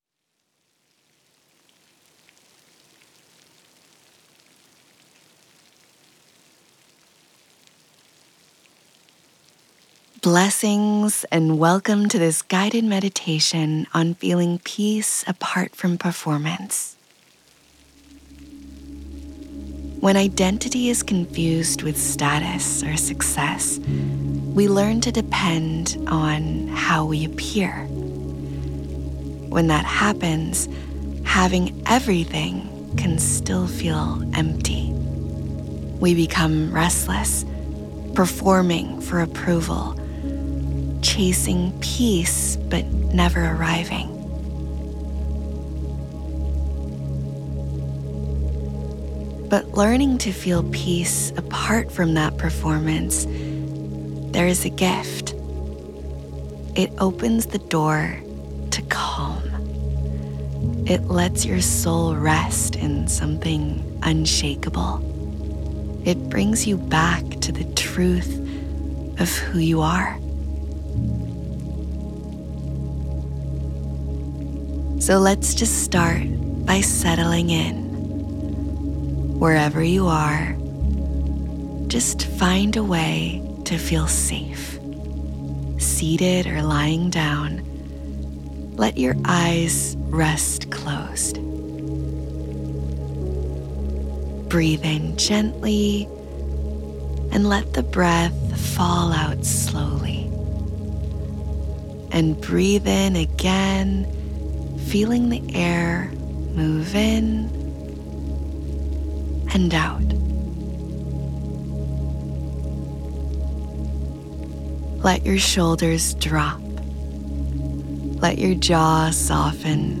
Example: Guided Meditation with sourced music and SFX CUSTOM ORIGINAL COMPOSITION $$$ If you’re after a truly unique sound